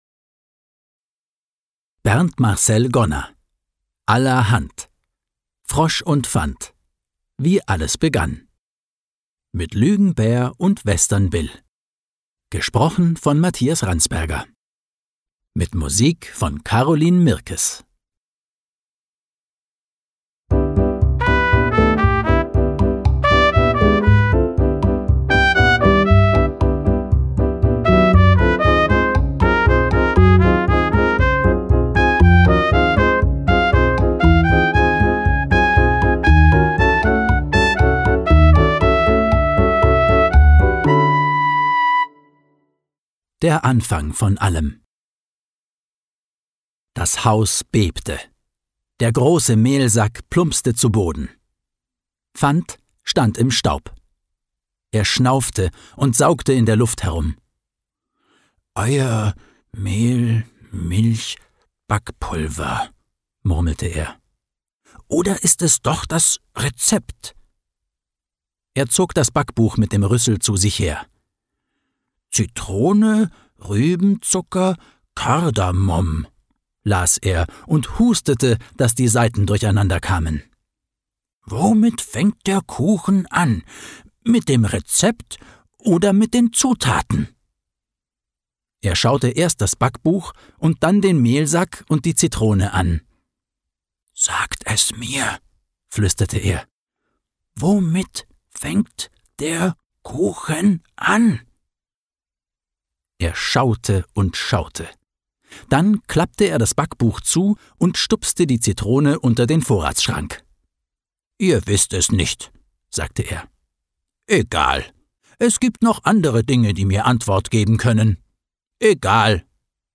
Schlagworte Herzenssache • Kinderhörbuch • Lesegeschichten